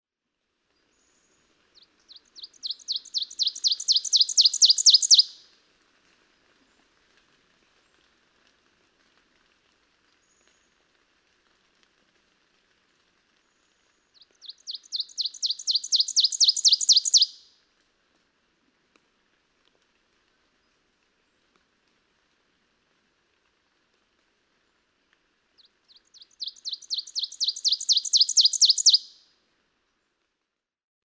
Ovenbird
Listen for the rather subtle differences in teacher intonations in the three songs from each of three neighboring males in examples 1, 2, and 3.
♫557. Example 3. June 5, 2017. Daughters of the American Revolution State Forest, Goshen, Massachusetts. (0:31)
557_Ovenbird.mp3